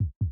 heartbeat-sound.tdVkehk1.wav